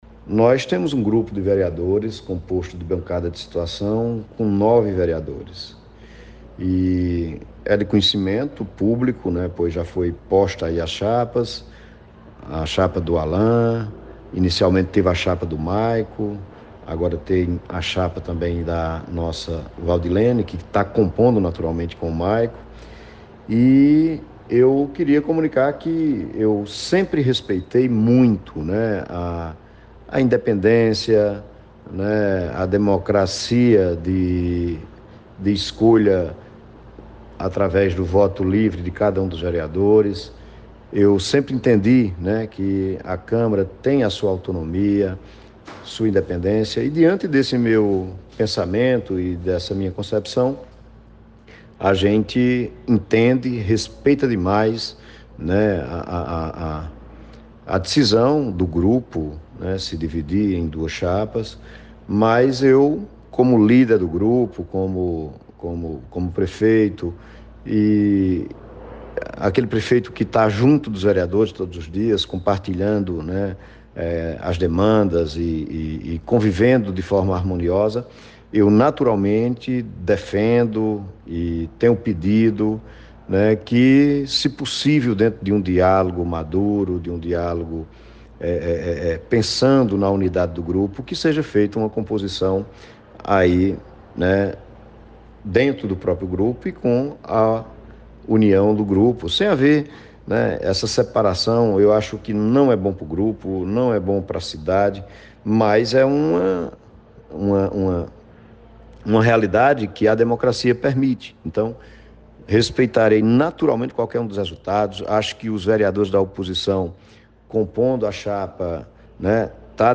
Helder enviou áudio na manhã desta quinta-feira, 8 de dezembro, para a Rádio Cultura [FM 96.3], horas após a vereadora Valdilene Bitu, PSD, ter anunciado seu nome na dianteira de uma chapa.